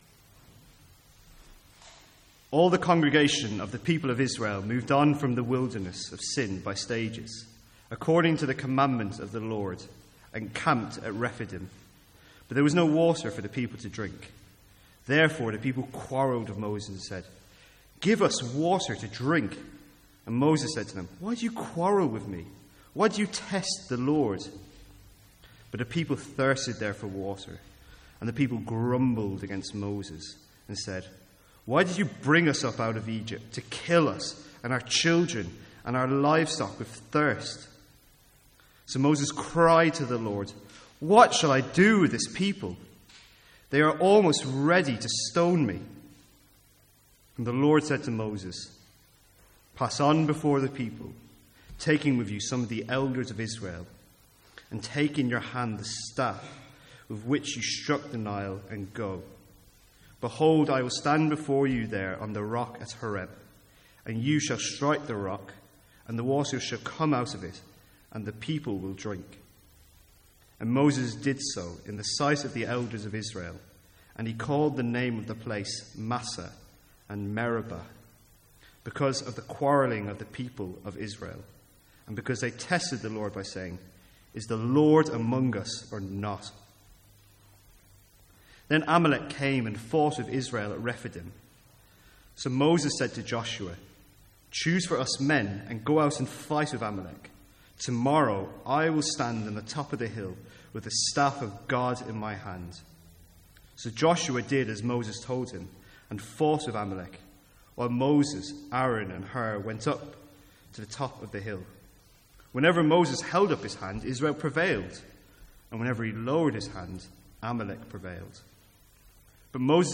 Sermons | St Andrews Free Church
Download Download From the Sunday evening series in Exodus.